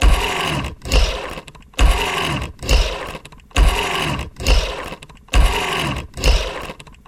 Звуки киберпанка
Звук шагов металлического робота или экзоскелета